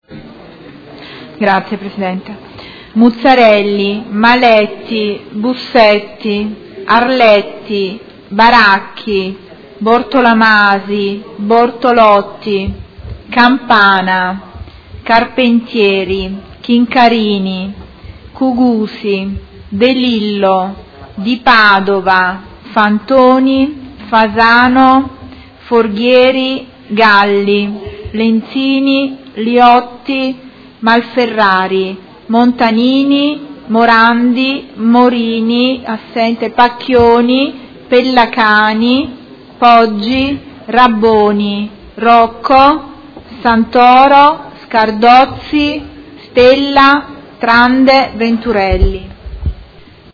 Segretario Generale — Sito Audio Consiglio Comunale
Seduta del 16/02/2017. Appello